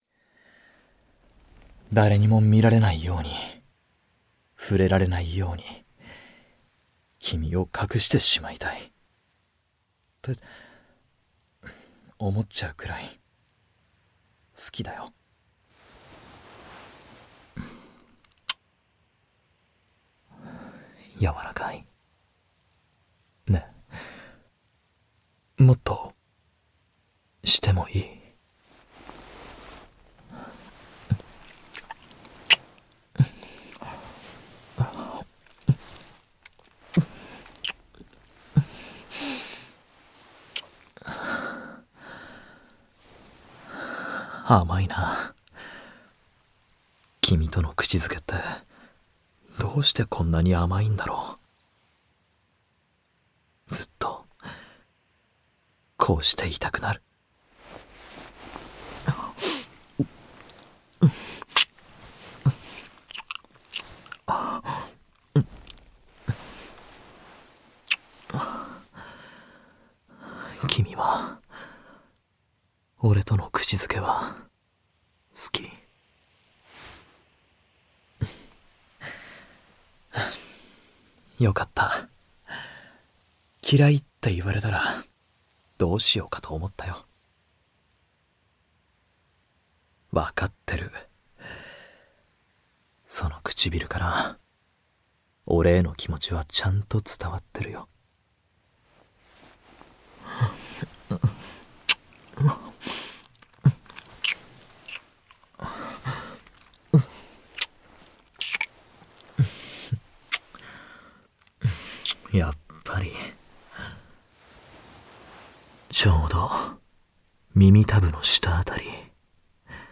●18歳以上推奨　●全編ダミーヘッドマイクにて収録